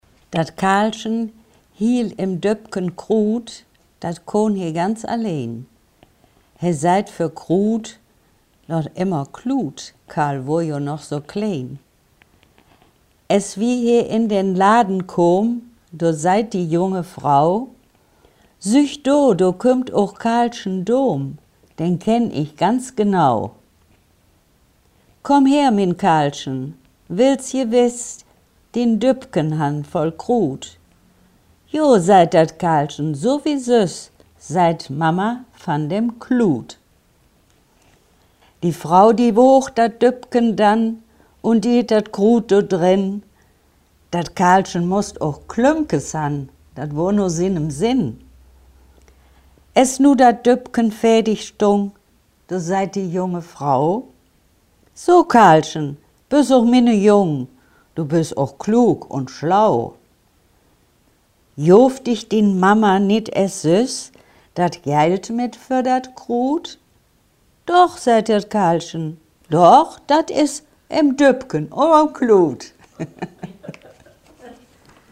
Offers-Kompeneï Velbert | Velberter Platt aus dem Bergischen Land
Gedicht-Dat_Karlschen.mp3